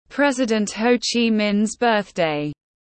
Ngày sinh nhật Bác tiếng anh gọi là President Ho Chi Minh’s Birthday, phiên âm tiếng anh đọc là /ˈprɛzɪdənt ˌhəʊ tʃiː ˈmɪn’s ˈbɜːθdeɪ/
President Ho Chi Minh’s Birthday /ˈprɛzɪdənt ˌhəʊ tʃiː ˈmɪn’s ˈbɜːθdeɪ/
President-Ho-Chi-Minhs-Birthday.mp3